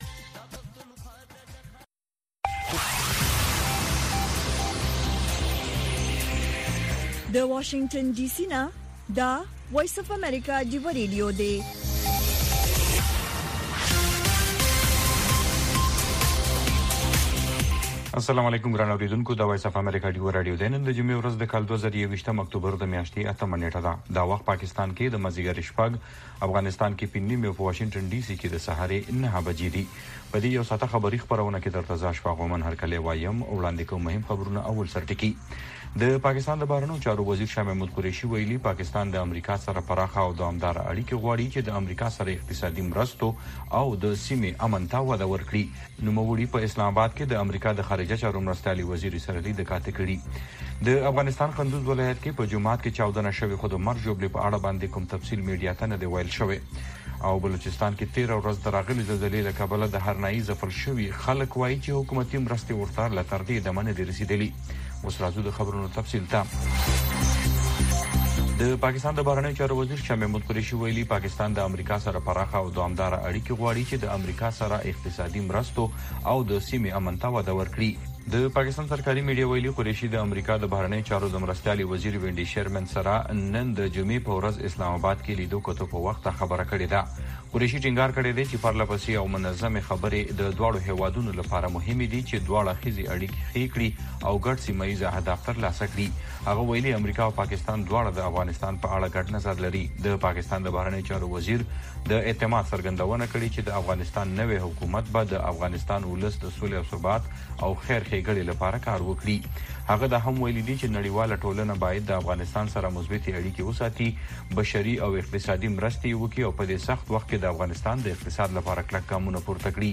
خبرونه
د وی او اې ډيوه راډيو ماښامنۍ خبرونه چالان کړئ اؤ د ورځې د مهمو تازه خبرونو سرليکونه واورئ.